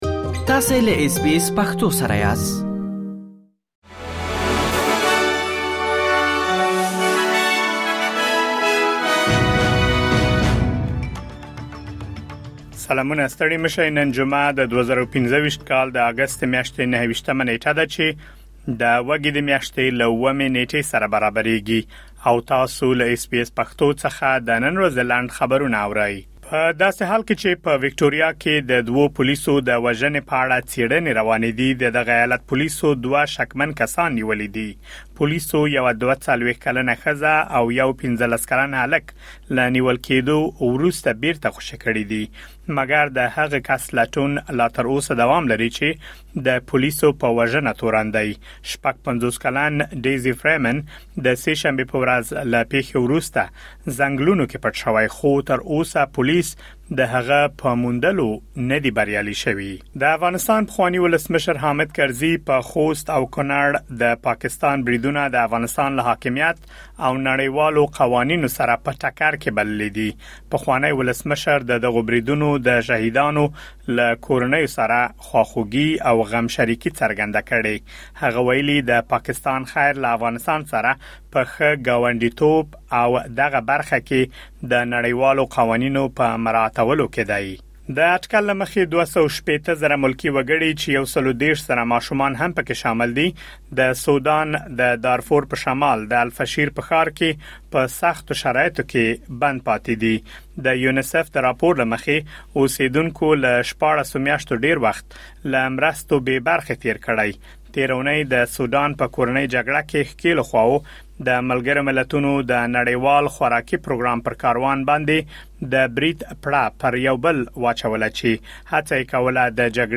د اس بي اس پښتو د نن ورځې لنډ خبرونه |۲۹ اګسټ ۲۰۲۵